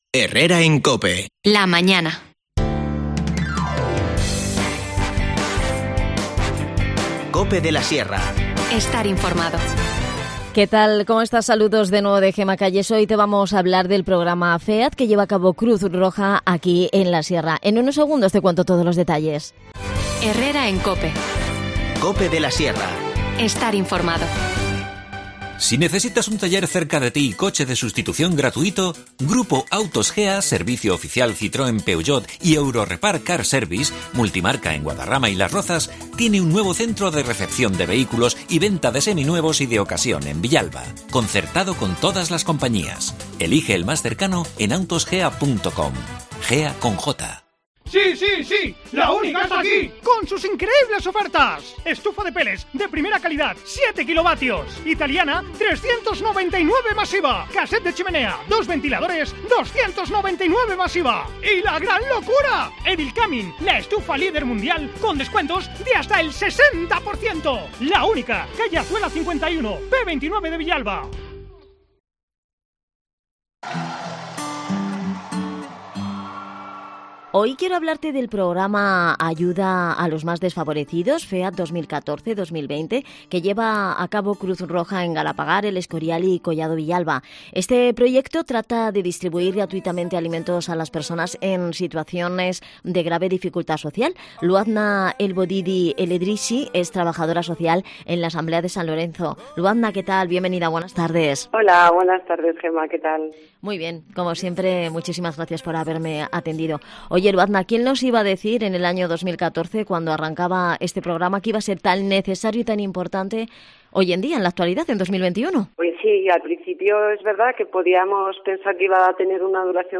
Redacción digital Madrid - Publicado el 14 abr 2021, 13:07 - Actualizado 17 mar 2023, 17:00 2 min lectura Descargar Facebook Twitter Whatsapp Telegram Enviar por email Copiar enlace Hoy hablamos con los profesionales de Cruz Roja sobre el Proyecto FEAD de ayuda a los más desfavorecidos. Esta iniciativa está cofinanciada por el fondo de ayuda europea y la Administración General del Estado. El objetivo que persigue es distribuir gratuitamente alimentos a las personas más desfavorecidas en situaciones de grave dificultad social de nuestros municipios.